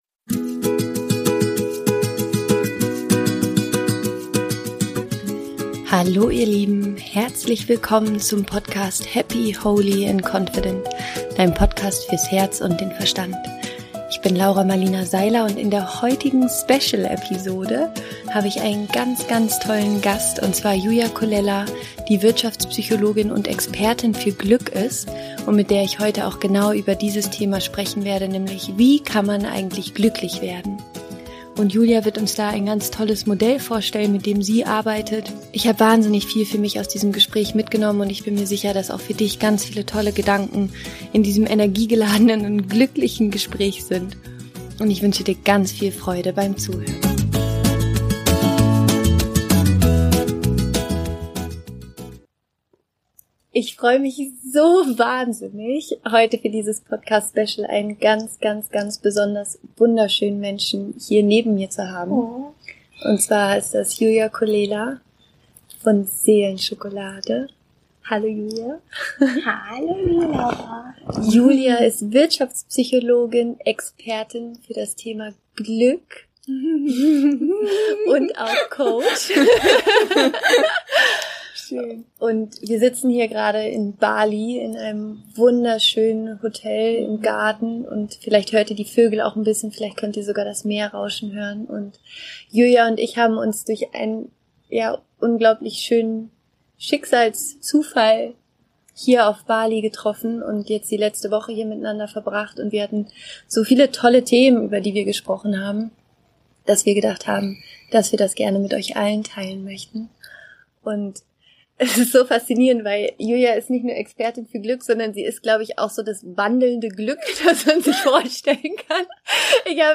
Wie werde ich glücklich? – Interview Special